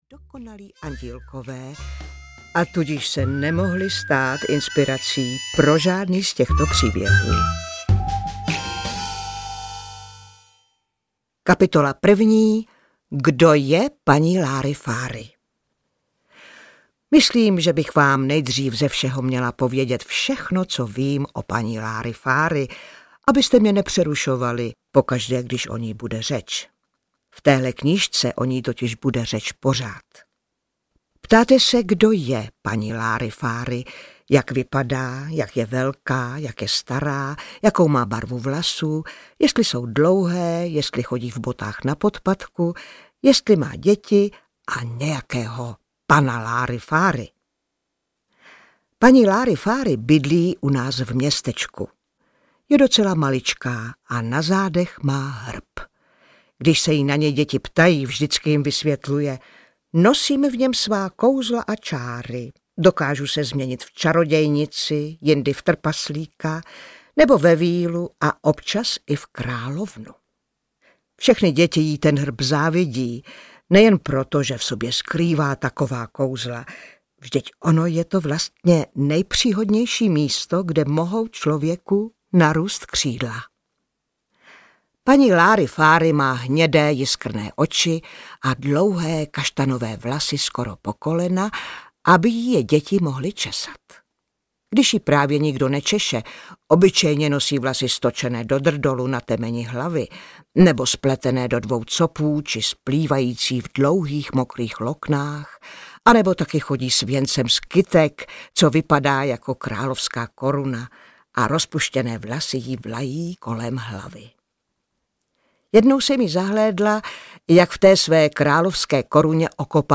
Paní Láryfáry audiokniha
Ukázka z knihy
• InterpretDana Syslová